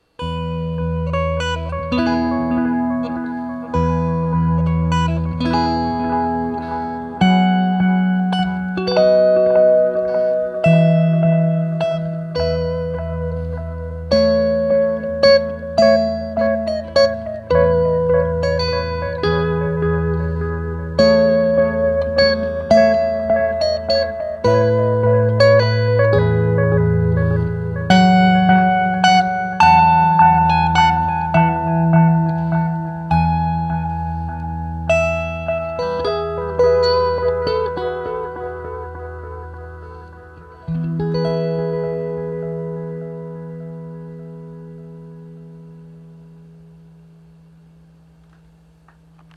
Pra um echo digital ele é bem analógico:
Bom, a gravação é um lixo, o guitarrista também, mas dá para sentir o drama!